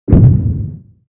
childexplosion4.wav